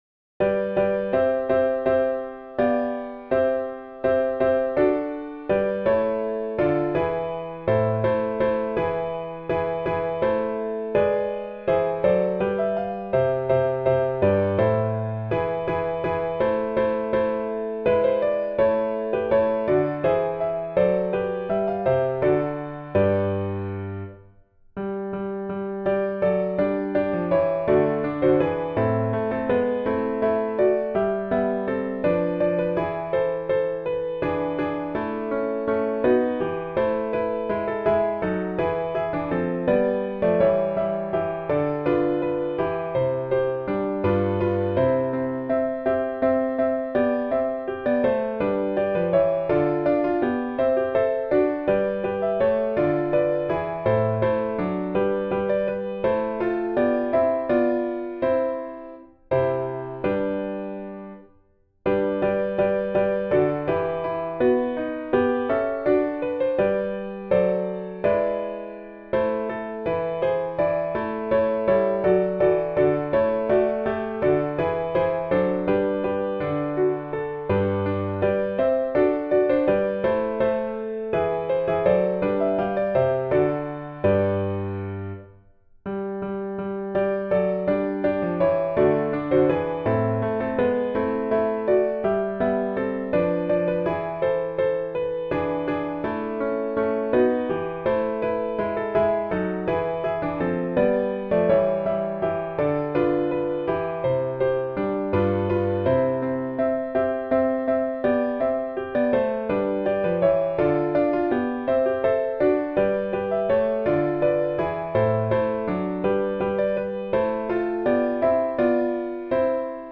A 4 voces (Tiple I, II, Alto y Tenor)